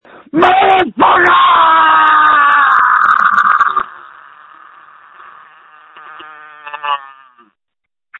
Screams from December 3, 2020
• When you call, we record you making sounds. Hopefully screaming.